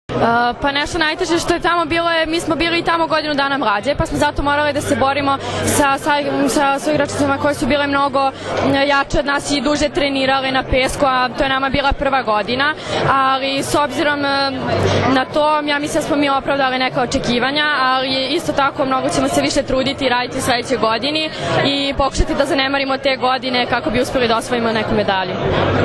Odbojkaški savez Srbije je danas u beogradskom hotelu “Metropol Palas” organizovao Novogdišnji koktel “Naša strana mreže”, na kojem su dodeljeni Trofeji “Odbojka spaja”, “Budućnost pripada njima”, Trofeji za najbolju odbojkašicu i odbojkaša i najbolju odbojkašicu i odbojkaša na pesku, kao i prvi put “specijalna plaketa OSS”.
IZJAVA